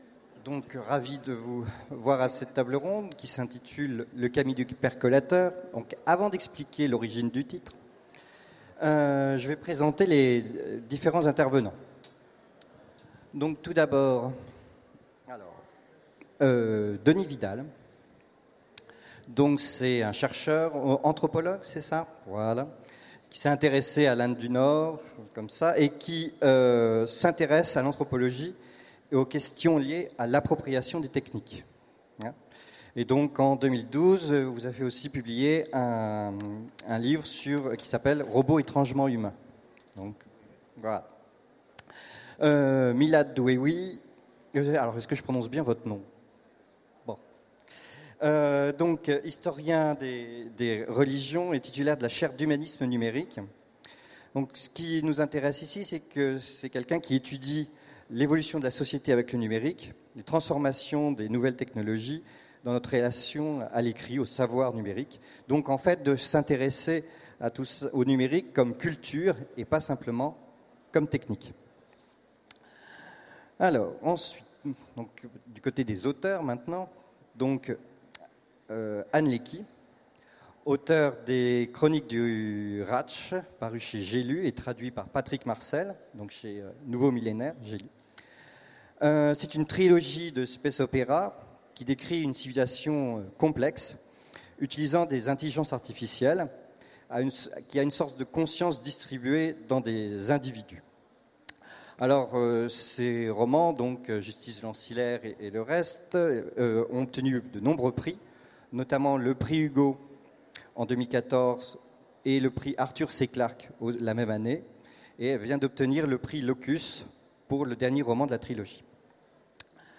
Utopiales 2016 : Conférence Le kami du percolateur